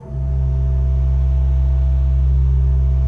Index of /90_sSampleCDs/Propeller Island - Cathedral Organ/Partition L/ROHRFLUTE MR